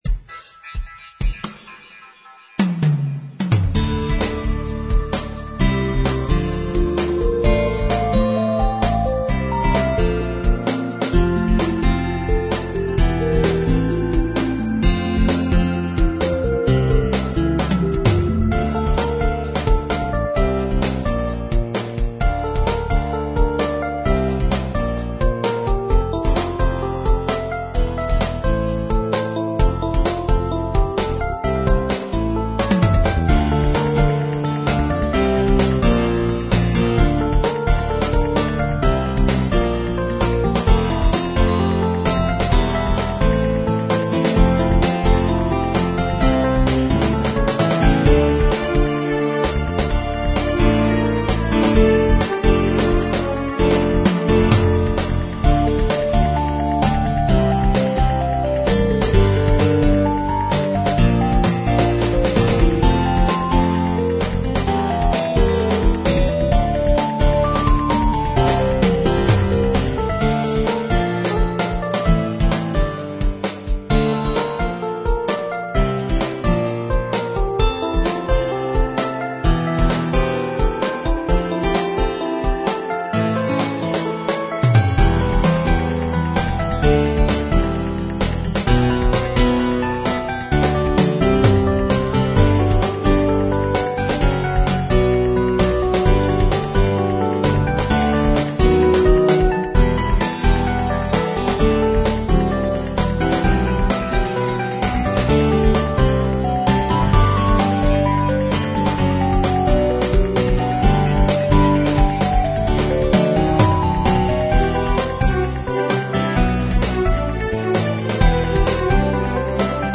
Dramatic Soundtrack music in style of Pop-Rock